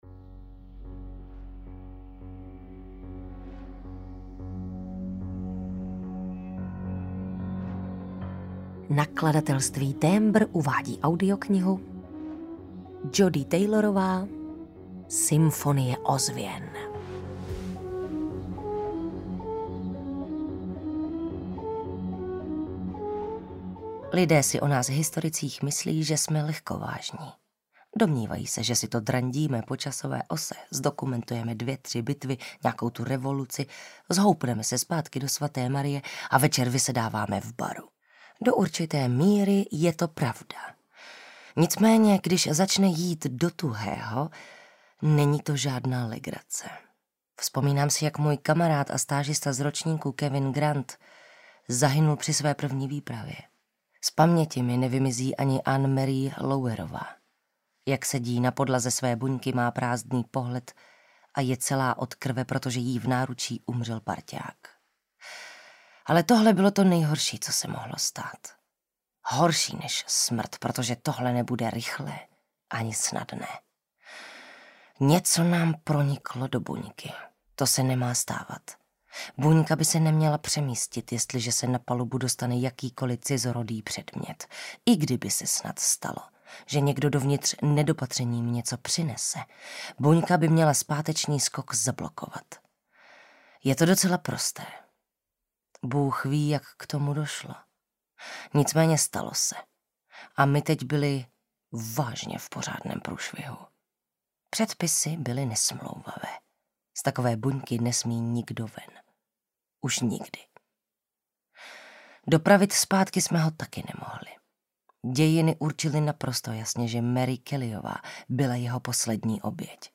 Symfonie ozvěn audiokniha
Ukázka z knihy